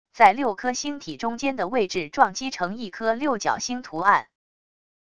在六颗星体中间的位置撞击成一颗六角星图案wav音频